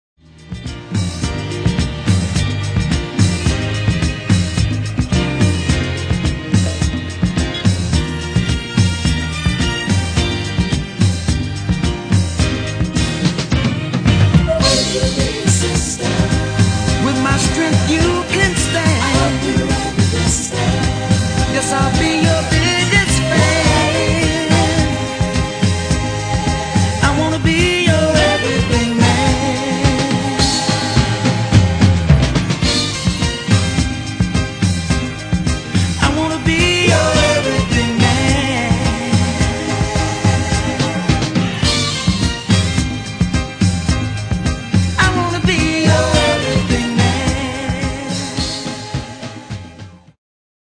Genere:   Disco | Soul | Funky